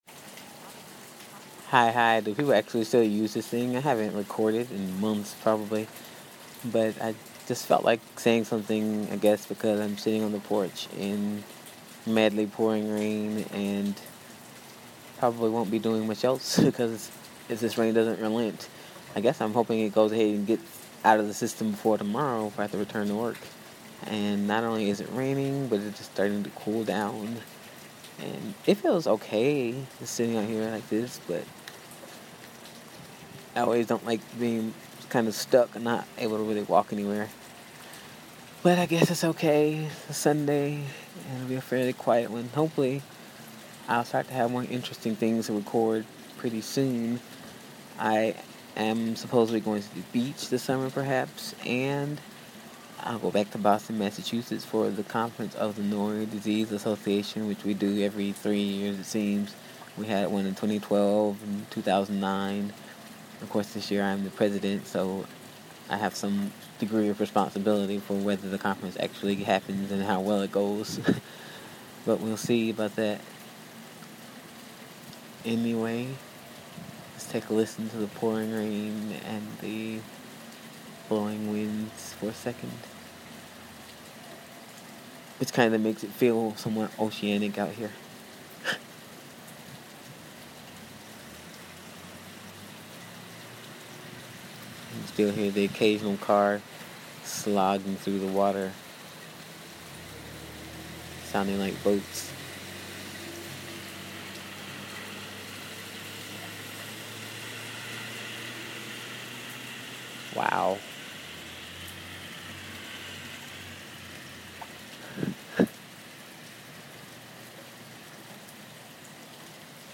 rain